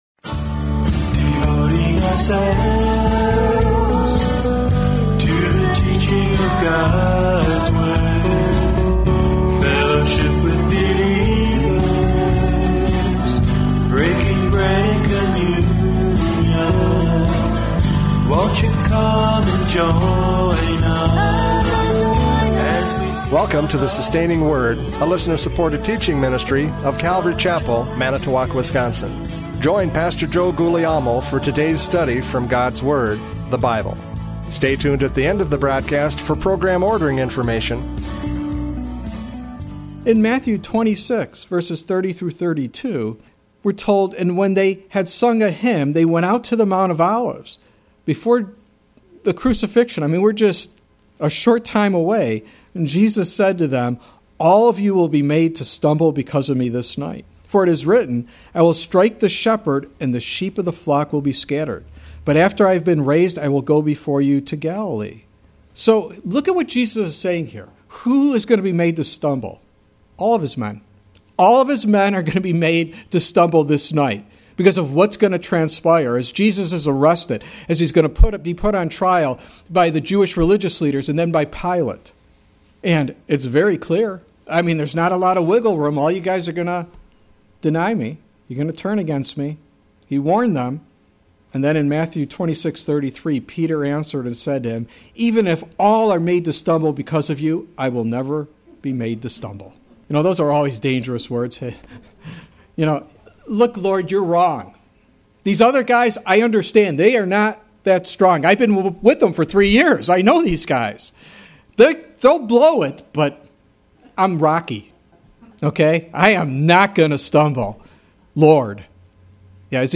John 4:27-42 Service Type: Radio Programs « John 4:27-42 Physical or Spiritual?